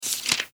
ページめくり2.mp3